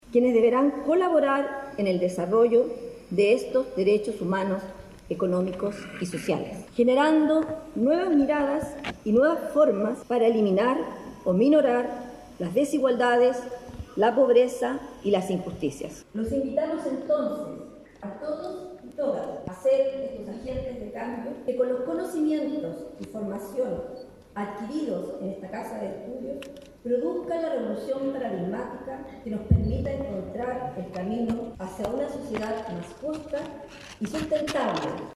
La actividad se llevó a cabo en el Auditorio del Campus Guayacán nuestra casa de estudios, hasta donde llegaron directivos, profesores, personal de la apoyo a la academia, y familiares para acompañar en tan importante momento a los graduandos.